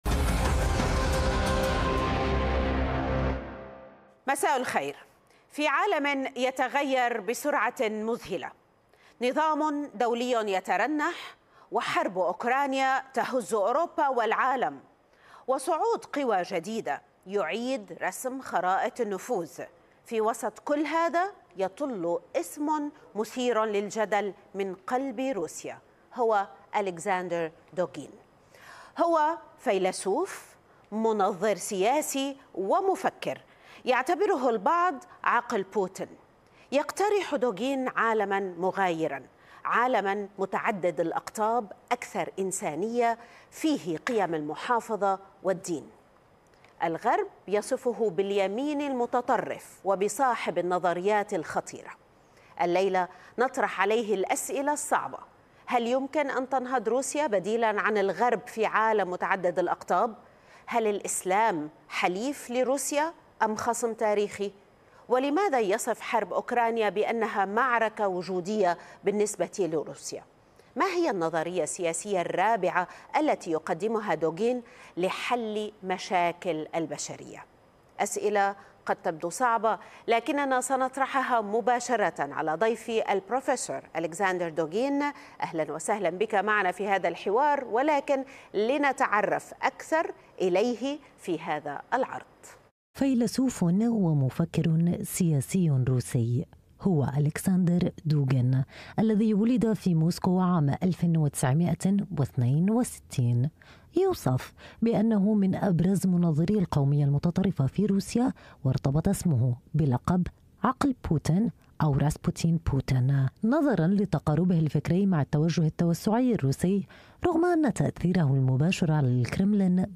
ألكسندر دوجين المفكر الروسي في مقابلة خاصة مع الشرق للأخبار